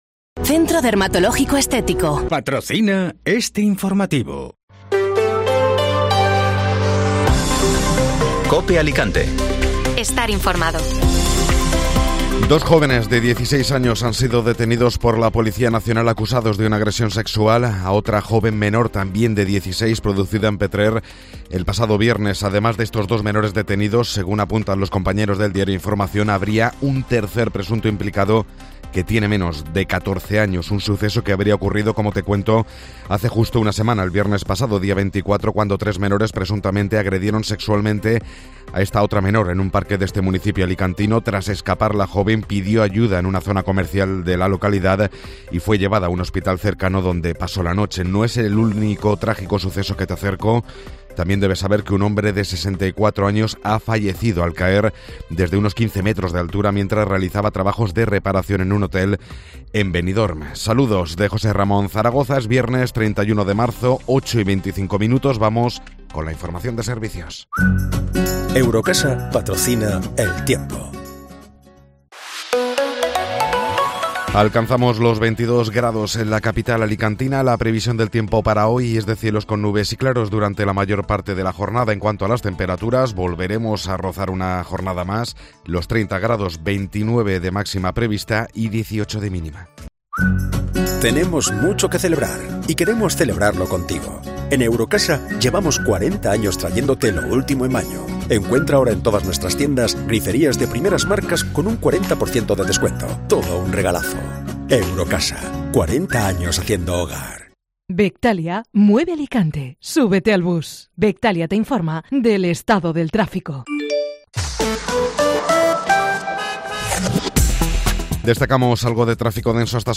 Informativo Matinal (Viernes 31 de Marzo)